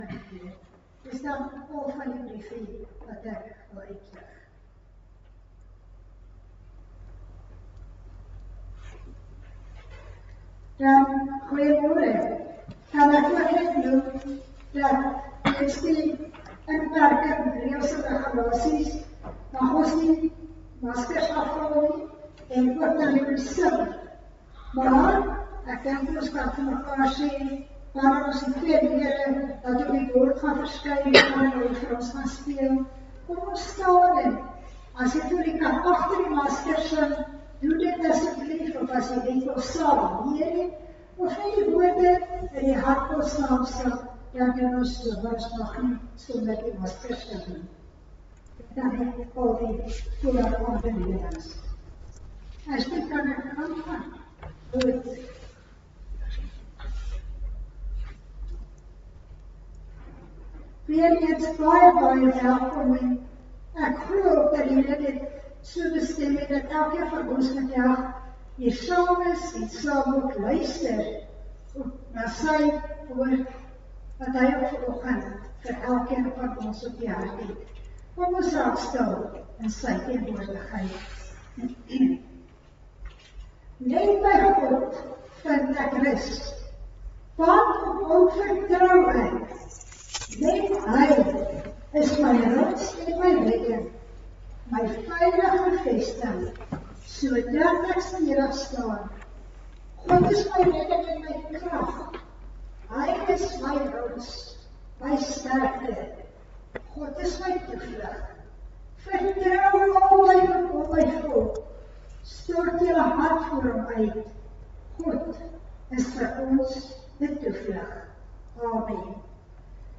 Erediens - 5 Julie 2020
Jammer oor die lae gehalte. Daar het tegniese probleme opgeduik wat ons nie tydens die uitsending kon regmaak nie.